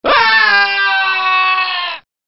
Download Half Life Scientist Dying sound effect for free.
Half Life Scientist Dying